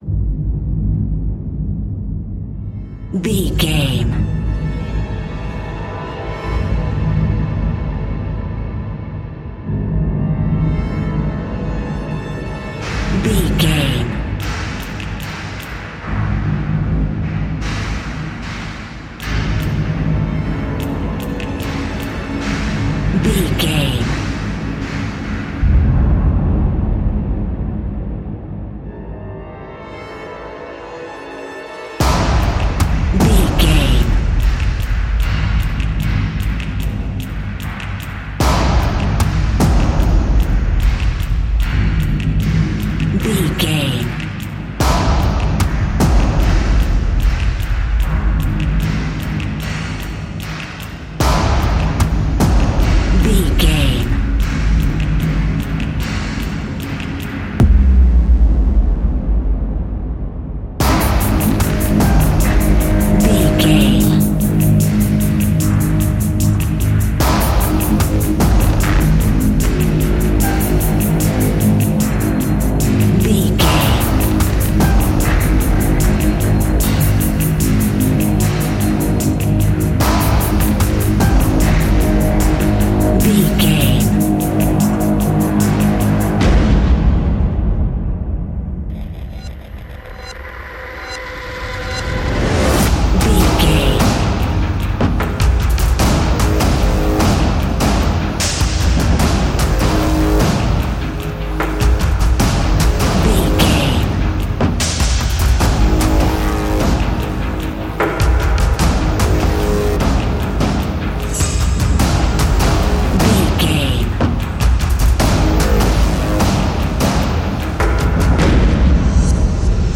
Fast paced
In-crescendo
Ionian/Major
industrial
dark ambient
EBM
synths
Krautrock